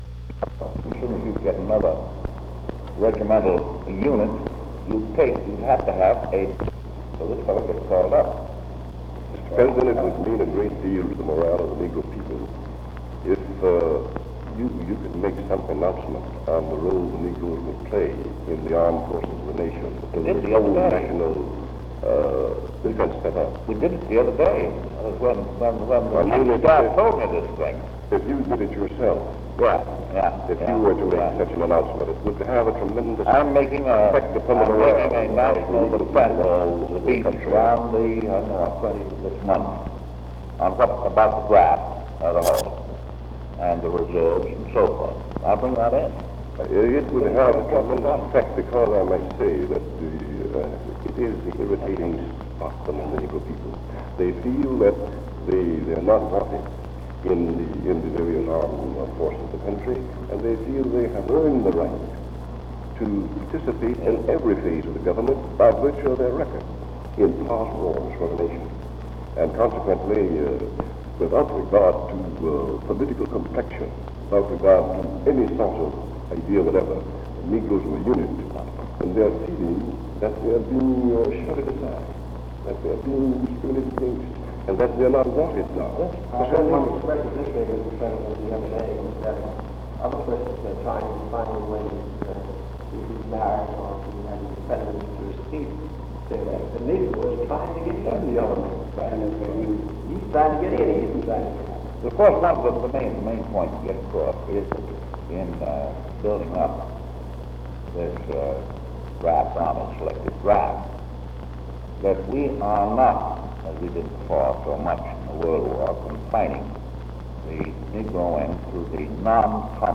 Office Conversation with A. Philip Randolph
Secret White House Tapes | Franklin D. Roosevelt Presidency